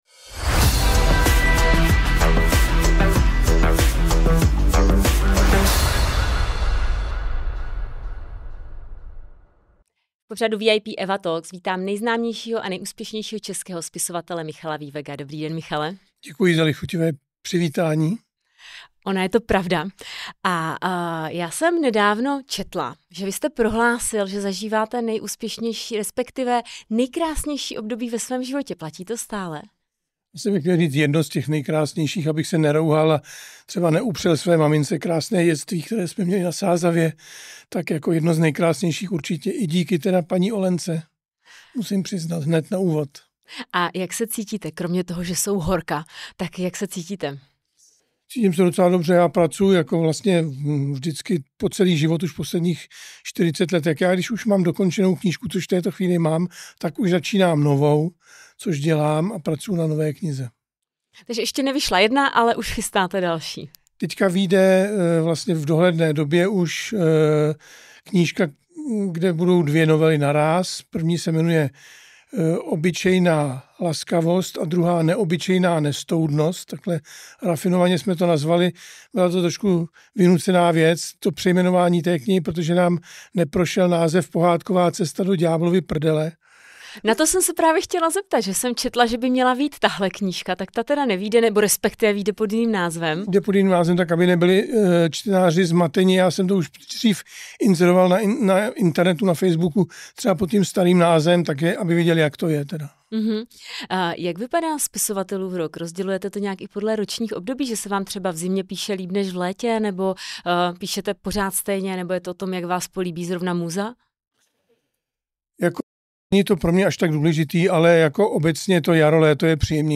Pozvání do studia tentokrát přijal spisovatel Michal Viewegh.